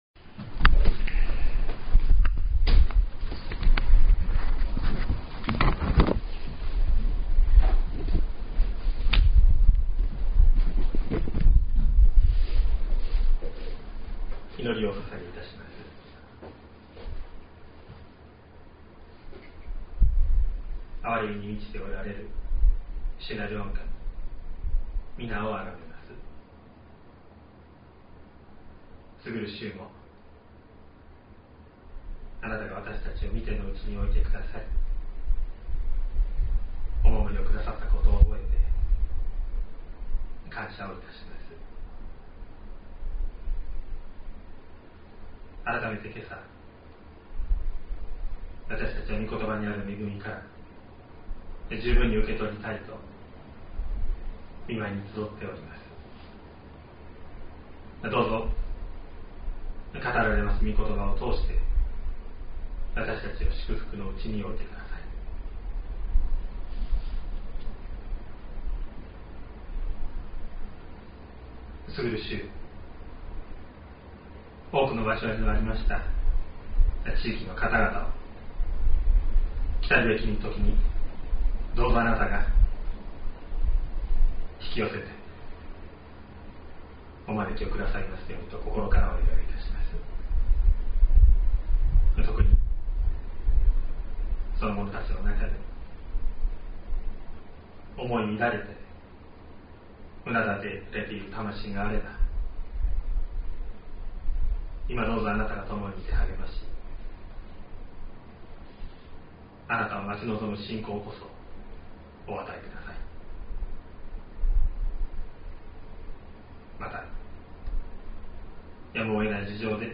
2023年08月20日朝の礼拝「心からあふれ出ること」西谷教会
説教アーカイブ。
音声ファイル 礼拝説教を録音した音声ファイルを公開しています。